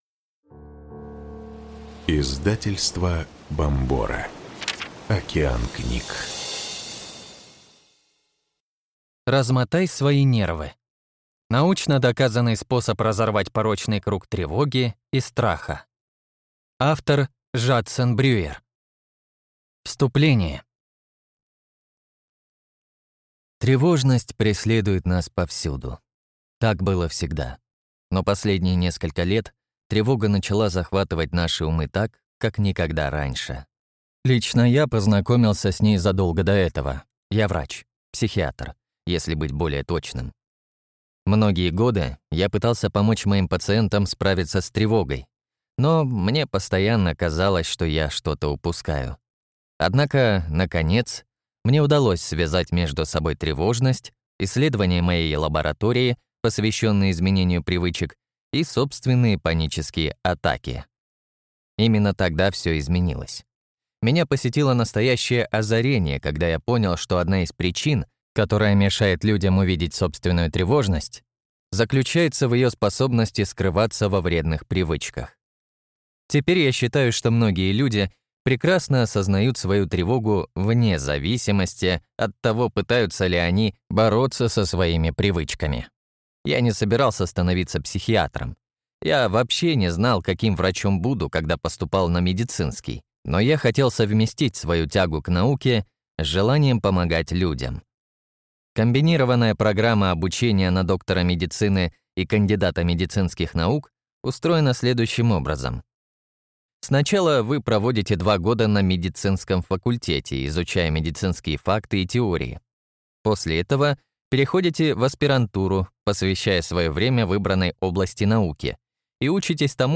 Аудиокнига Размотай свои нервы. Научно доказанный способ разорвать порочный круг тревоги и страха | Библиотека аудиокниг